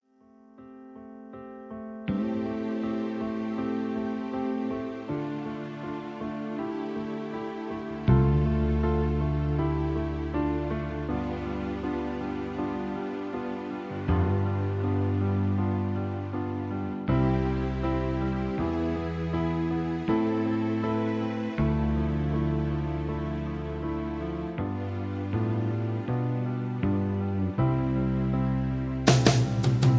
karaoke , backing tracks